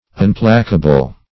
Unplacable \Un*pla"ca*ble\, a.
unplacable.mp3